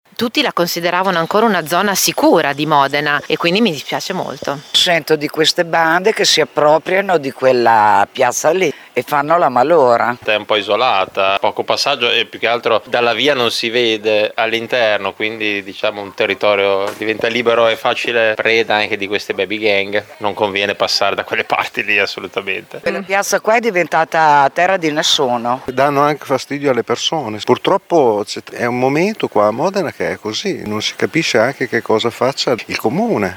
Anche quella zona, con il parcheggio sottostante, è diventata terra di nessuno e pericolosa soprattutto di sera, come raccontano alcuni residenti: